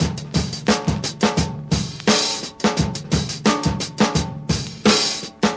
• 86 Bpm Drum Groove E Key.wav
Free drum beat - kick tuned to the E note. Loudest frequency: 2503Hz
86-bpm-drum-groove-e-key-ozZ.wav